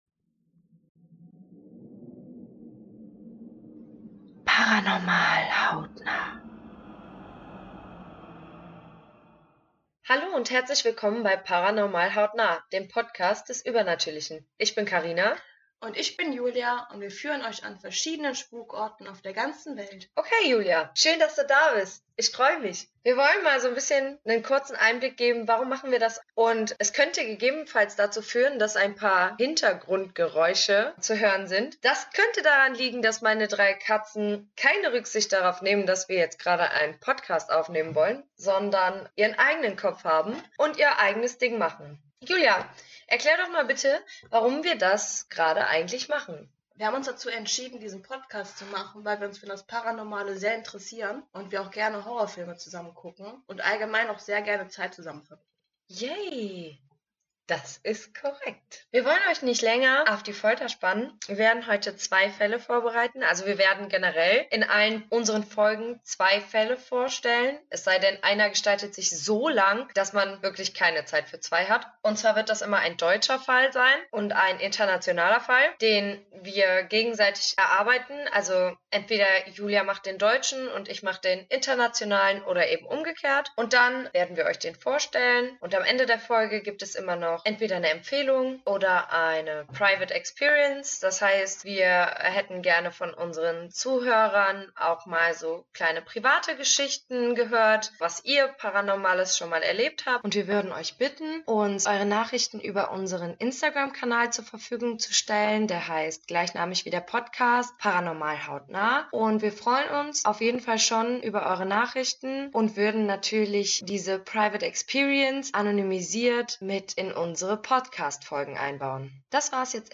In unserer ersten Folge geht es um das Winchester Mystery House und die mysteriösen Ereignisse am Grabowsee in Brandenburg. Bitte verzeiht uns, wenn der Schnitt nicht immer 100% passt. Wir machen den Podcast hobbymäßig und haben bisher kaum Erfahrungen, was Ton und dazugehörige Technik angeht.